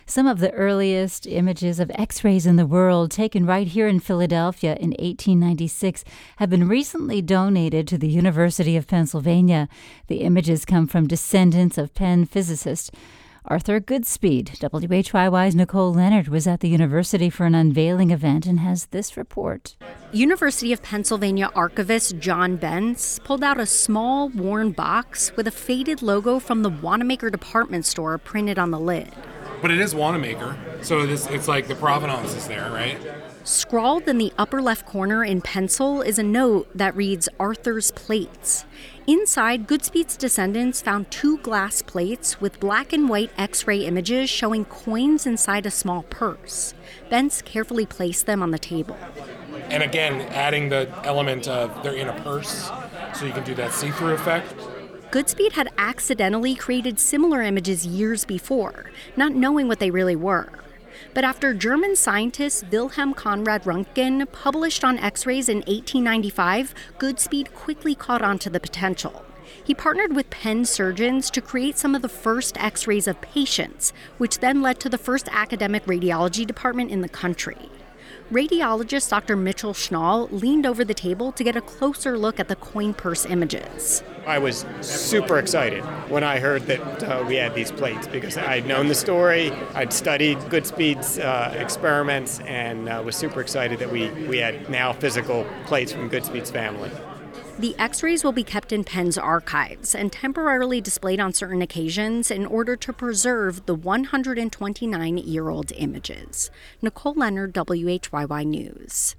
Brought to you by Radio Times Radio Times WHYY's Radio Times is an engaging and timely call-in program that tackles wide-ranging issues of concern to listeners in the Delaware Valley.